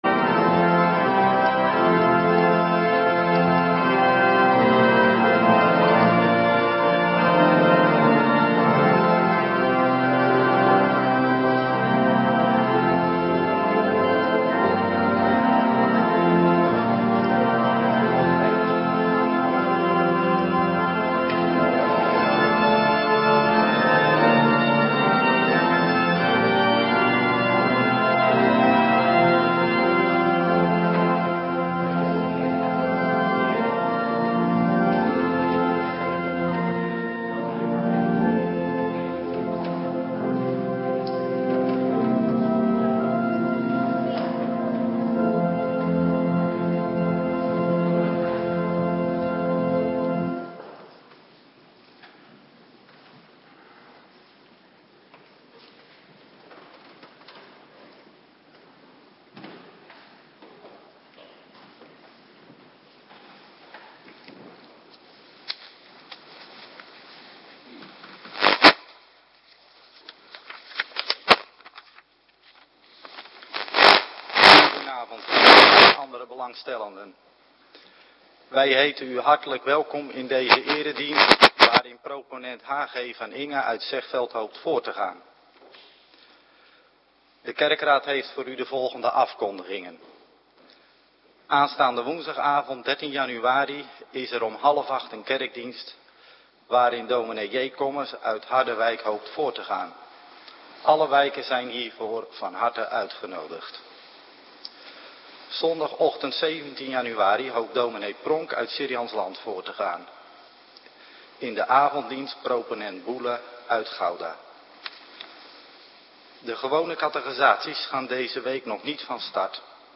Avonddienst - Cluster 3
Locatie: Hervormde Gemeente Waarder